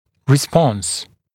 [rɪ’spɔns][ри’спонс]реакция, реагирование, ответная реакция